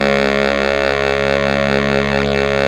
Index of /90_sSampleCDs/Roland LCDP07 Super Sax/SAX_Sax Ensemble/SAX_Sax Sect Ens
SAX 2 BARI0G.wav